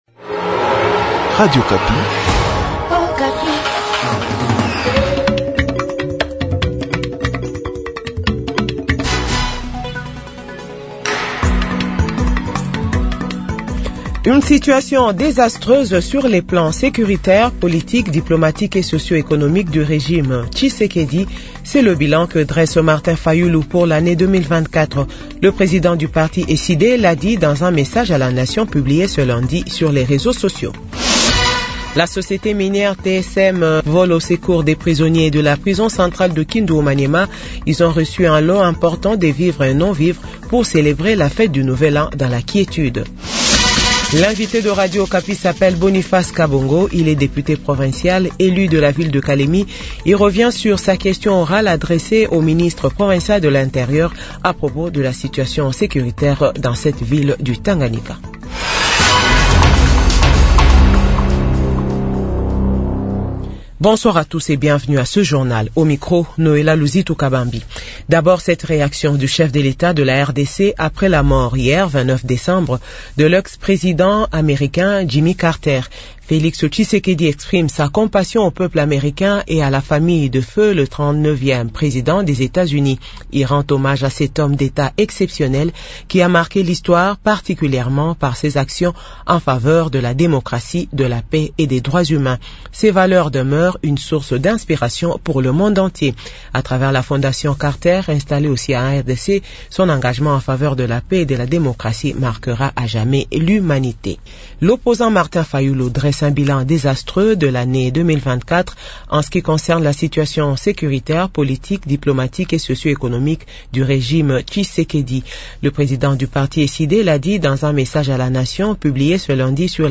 JOURNAL FRANCAIS DE 18H00